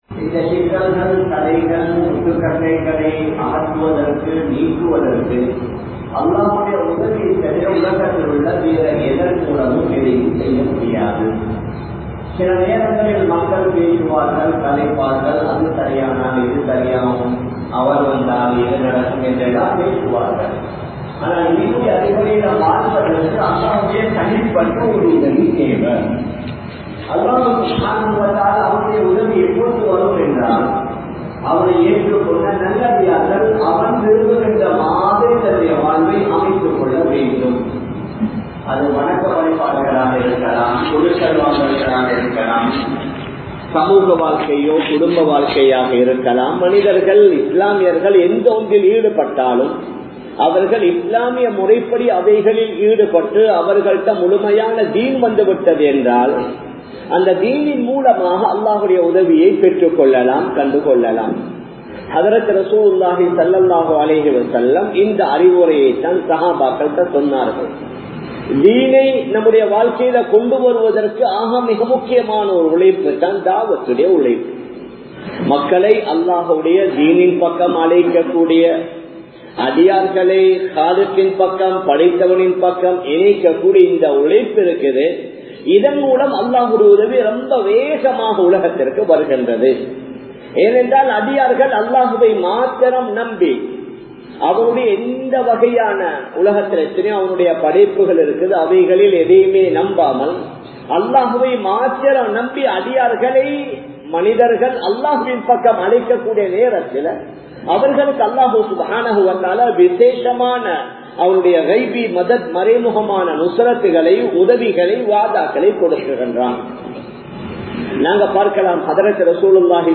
Thiyaaham & Dhawath (தியாகம் & தஃவத்) | Audio Bayans | All Ceylon Muslim Youth Community | Addalaichenai
Colombo, GrandPass Markaz